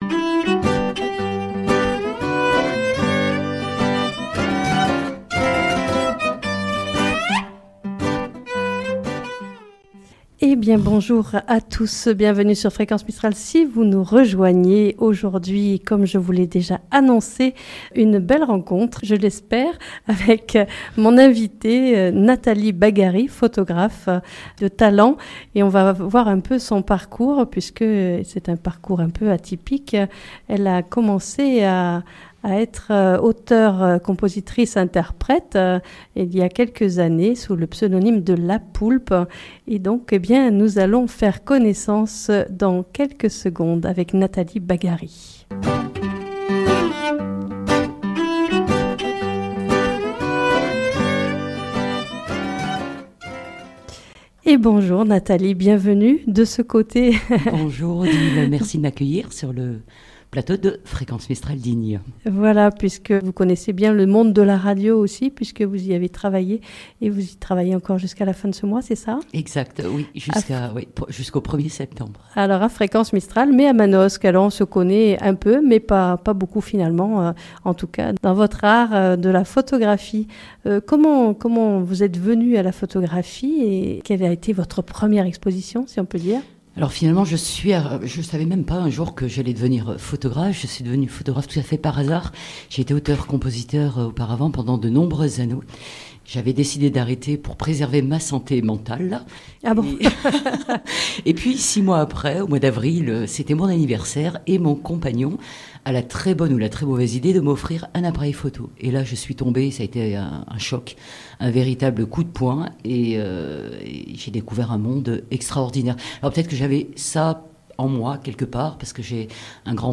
Au cours de cet entretien